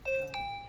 private_message.wav